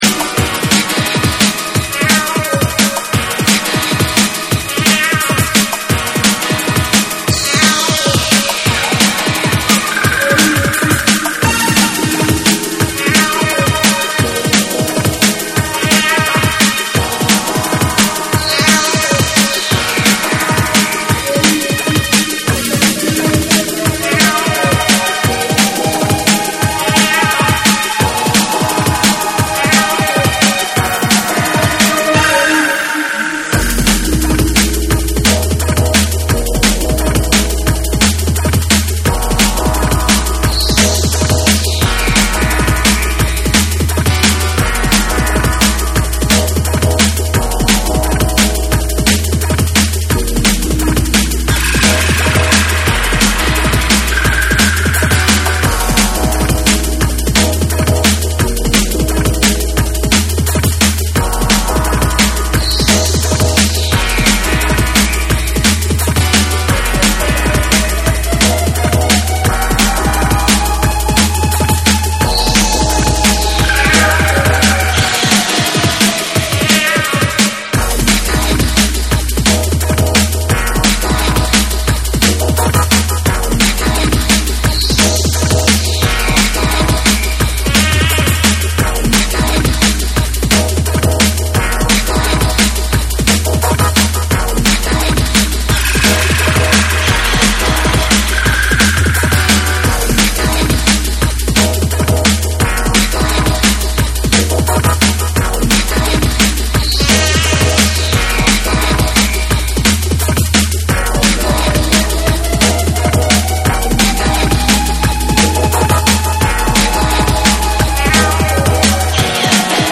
ダイナミックで爽快なトラックに、宙を舞うスペイシーで空間的なシンセが溶け込む
JUNGLE & DRUM'N BASS